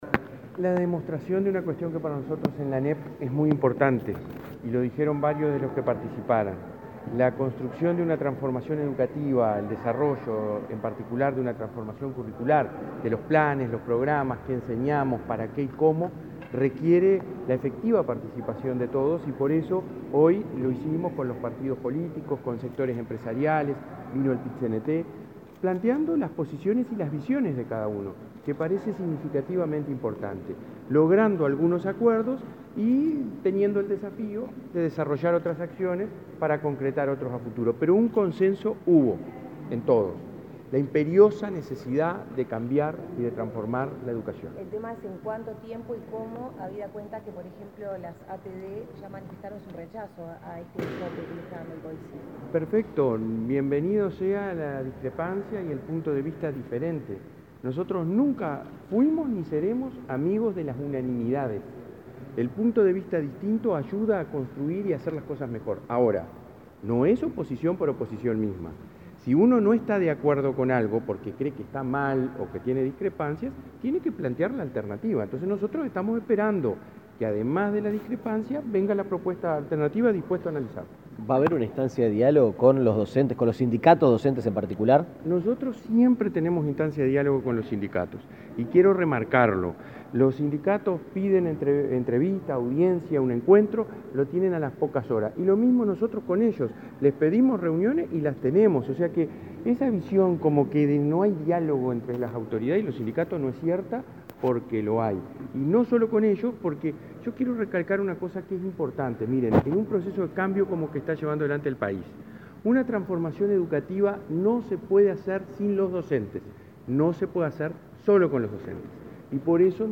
Declaraciones a la prensa del presidente de la ANEP, Robert Silva
Luego de la apertura del encuentro, el presidente de la ANEP, Robert Silva, dialogó con la prensa.